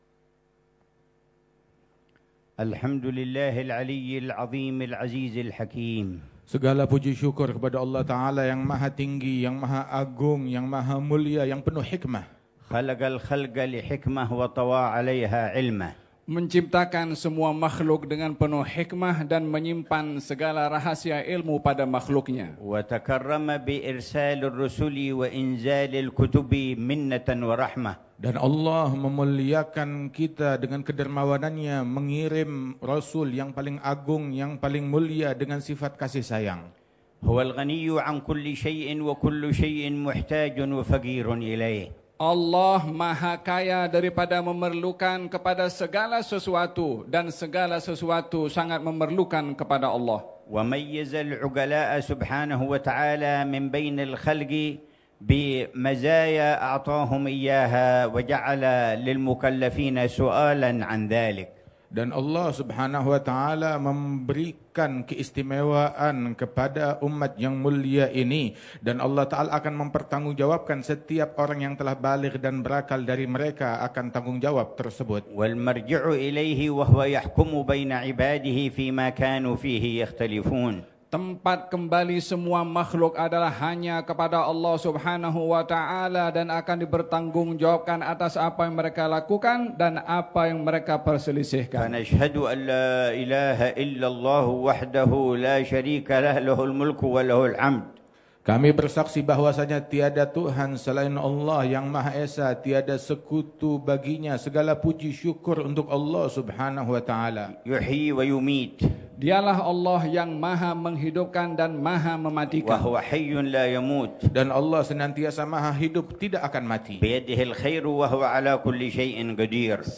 محاضرة العلامة الحبيب عمر بن محمد بن حفيظ في المجلس الكبير في استاد سلطان ناصر الدين شاه، بولاية ترنغانو، ماليزيا، ليلة الأربعاء 23 ربيع الثاني 1447هـ بعنوان: